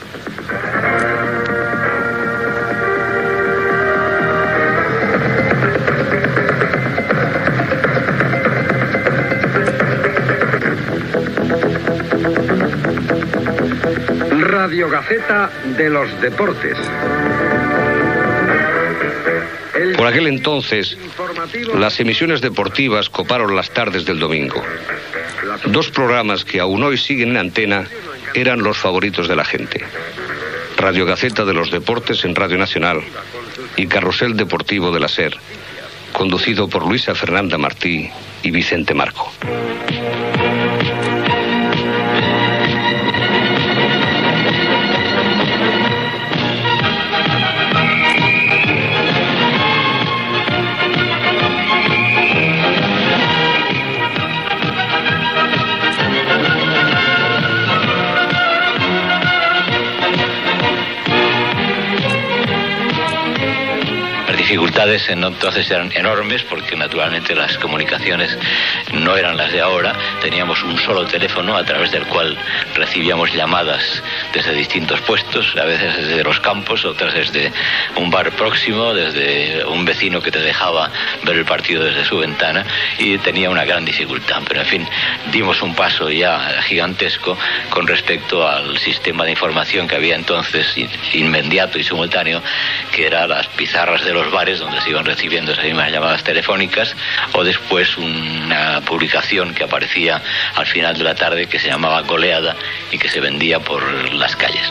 Careta de "Radiogaceta de los deportes" (amb la veu de Juan Manuel Gozalo) de RNE, sintonia de "Carrusel deportivo" de la Cadena SER i Vicente Marco que parla de Carrusel